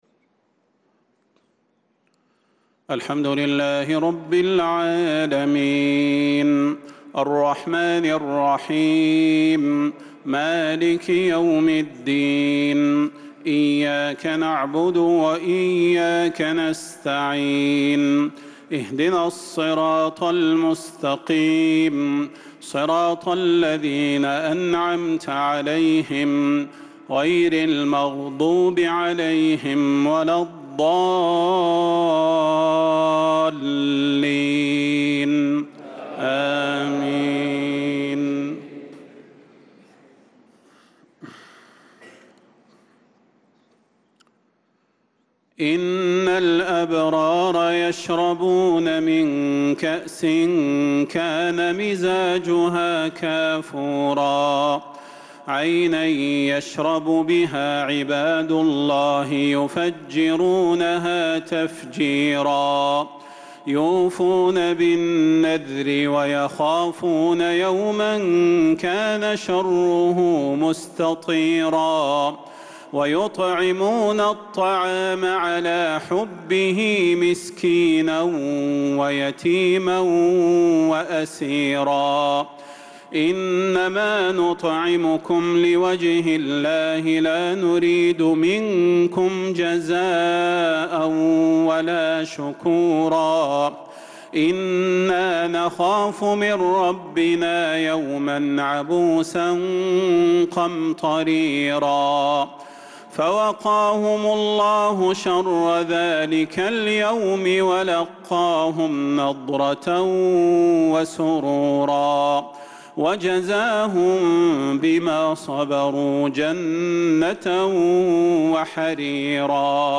صلاة العشاء للقارئ صلاح البدير 13 شوال 1445 هـ
تِلَاوَات الْحَرَمَيْن .